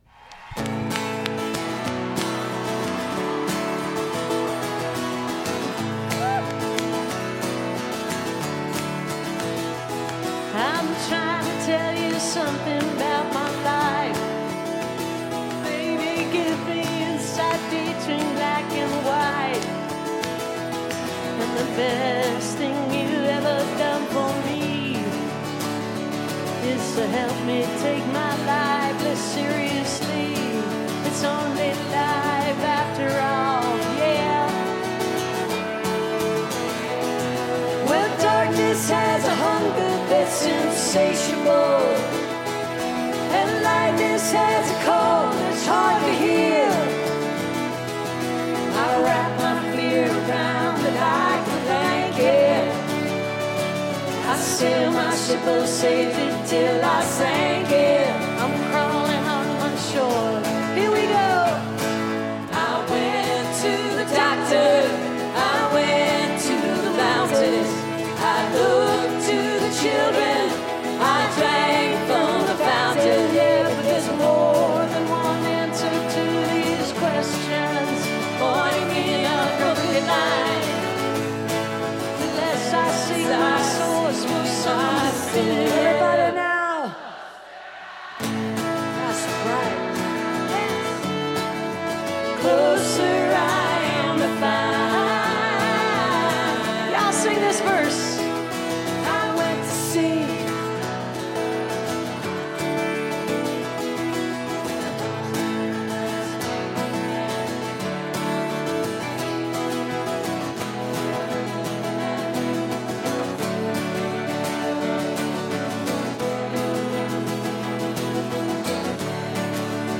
recorded from webcast